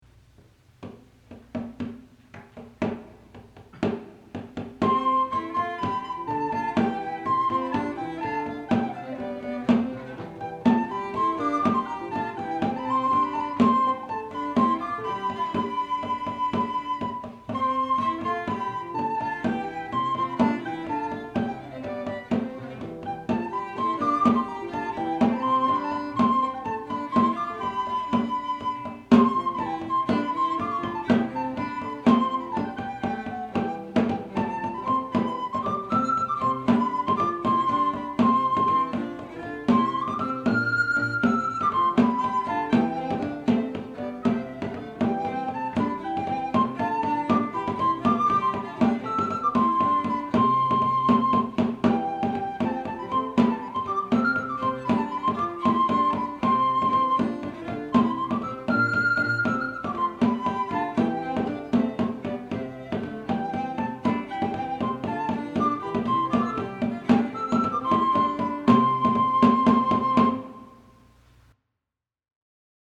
Sixty of the pieces are untexted and presumably for instruments.
| Chamber Consort Instruments 'Food of Love' 1982